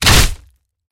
Вы можете слушать и скачивать мощные удары по стенам, грохот бронзового наконечника и другие эффекты, воссоздающие атмосферу средневековых битв.
Звук удара Таран - Есть такая возможность